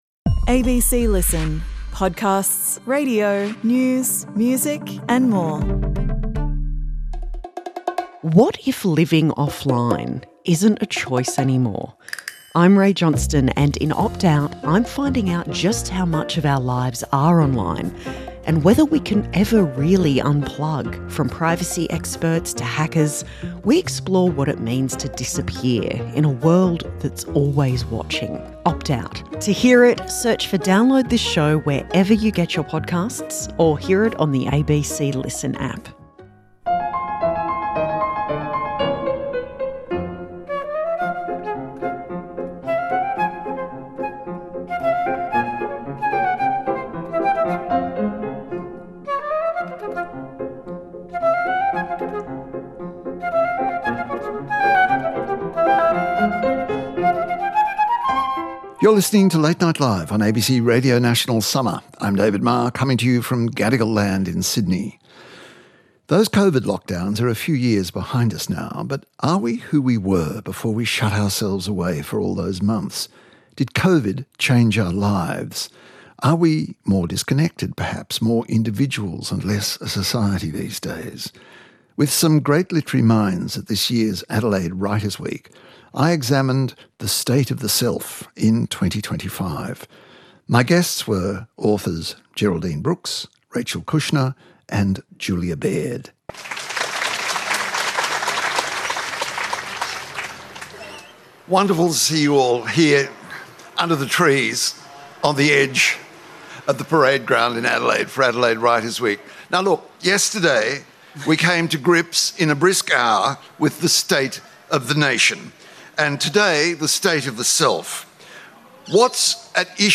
1 LNL Summer: Geraldine Brooks, Rachel Kushner and Julia Baird at Adelaide Writers Week 2025 54:26